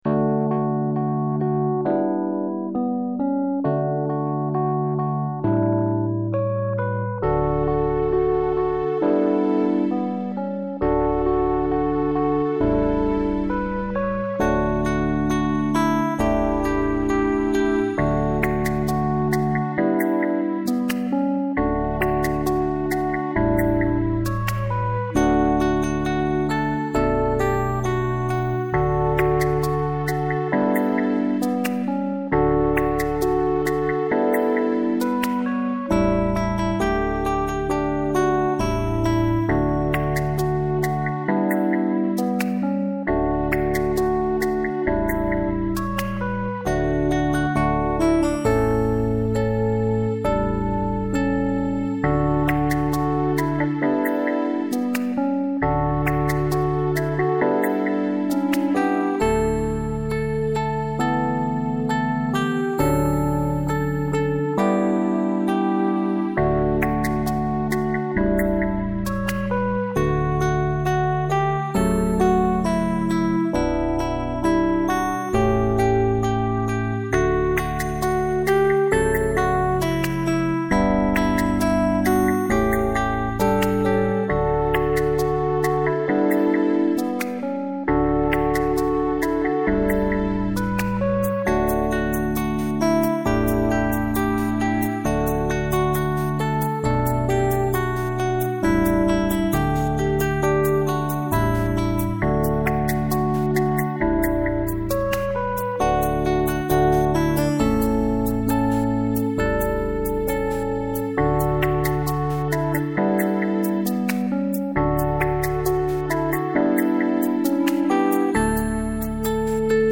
la base in C ;